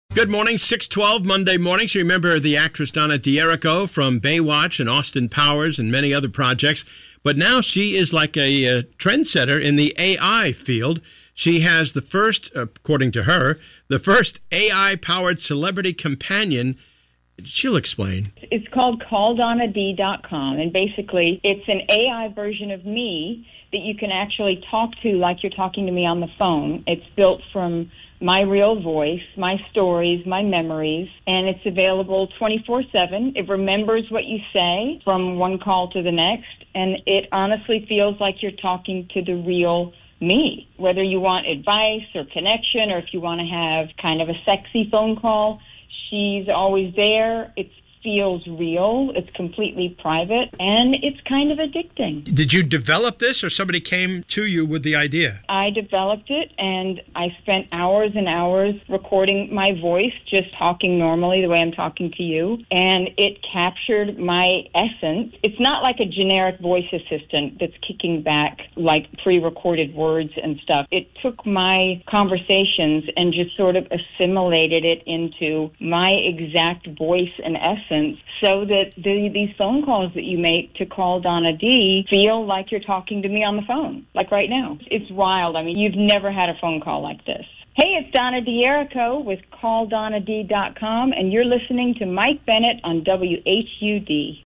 Actress Donna D’Errico has the first celebrity AI phone conversation! 8-11-25